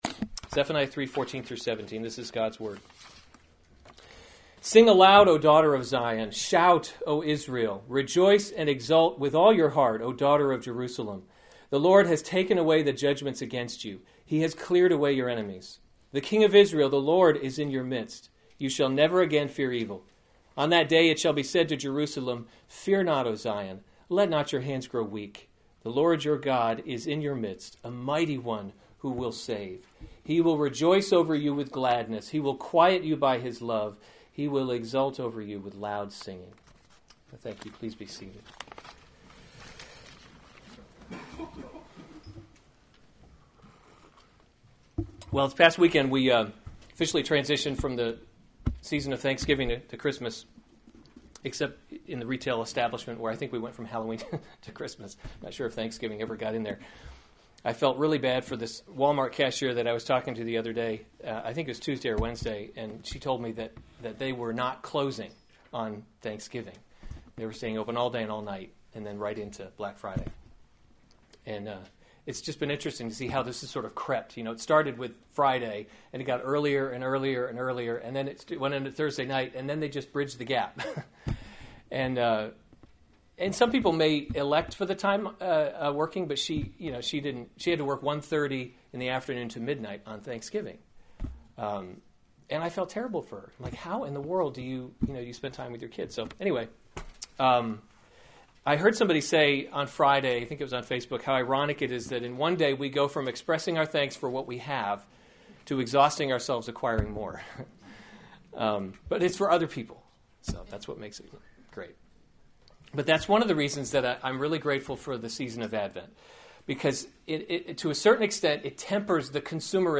Weekly Sunday Service
sermon